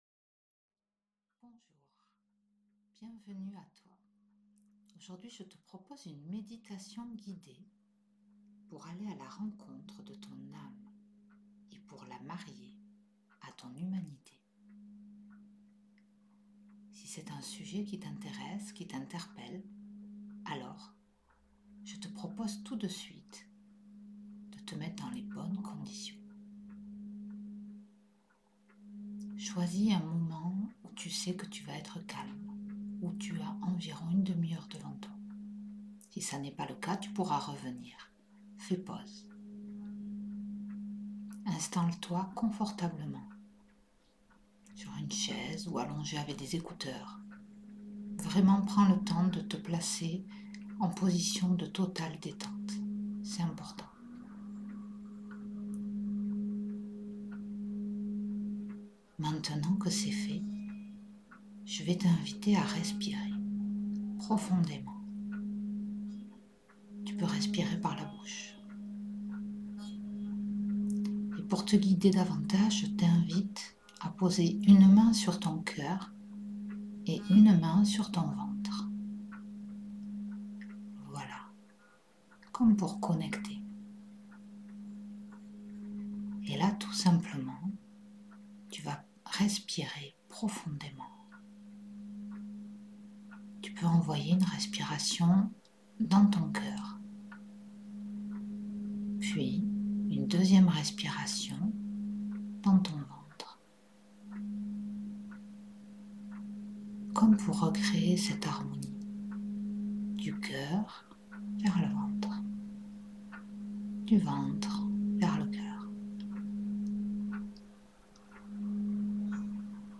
Méditation guidée "Rencontre de ton âme"durée 20 min
Meditation-guidee-A-la-rencontre-de-ton-ame.mp3